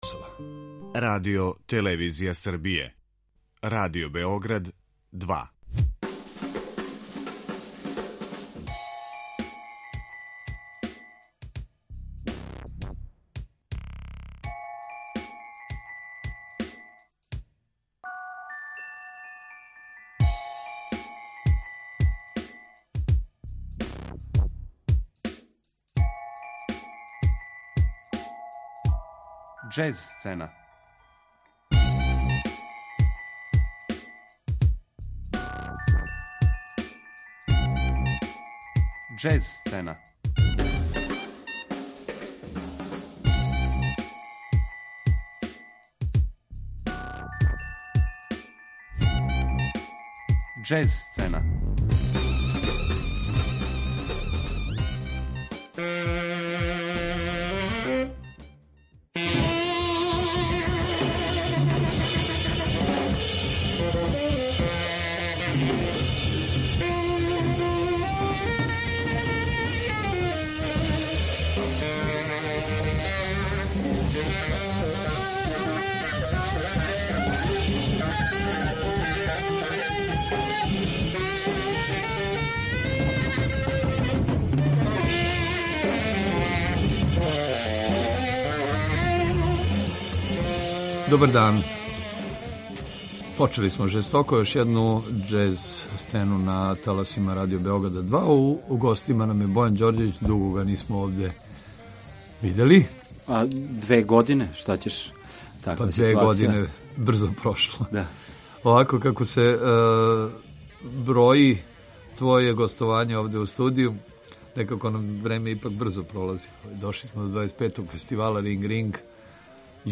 Гост у студију Радио Београда 2